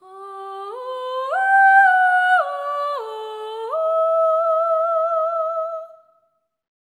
ETHEREAL13-L.wav